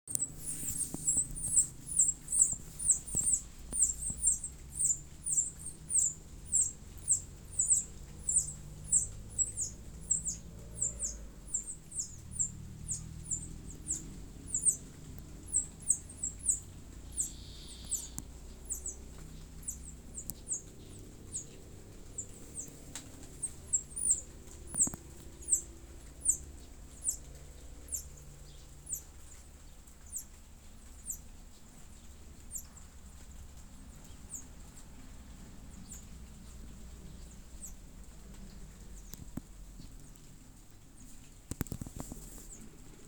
Ratona Común (Troglodytes musculus)
Se escucha la vocalizacion de las crias que aun no se dejan ver en el nido.
Fase de la vida: Pichón
Localidad o área protegida: Santa María
Certeza: Vocalización Grabada
ratona-crias.mp3